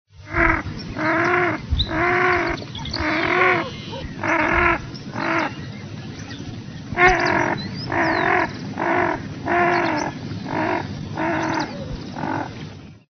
PATO PICAZO (Netta peposaca).
pato-picazo.mp3